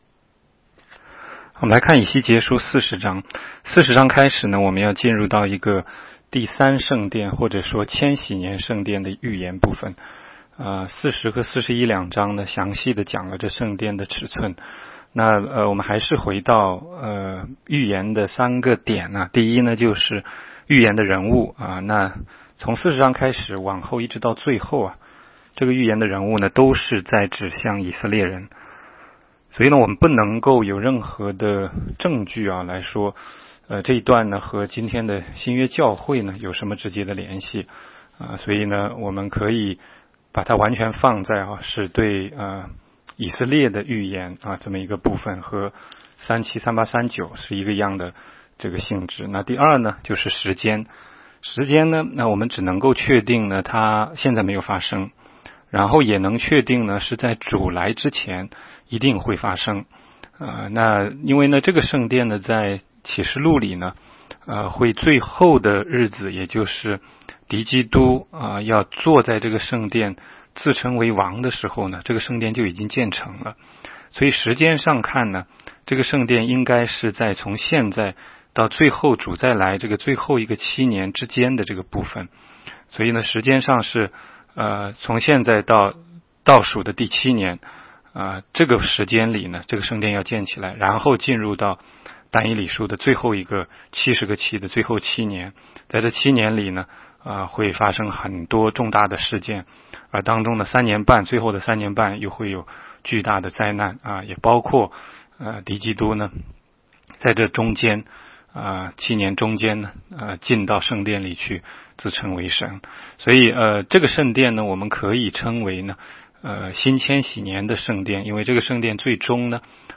16街讲道录音 - 每日读经 -《以西结书》40章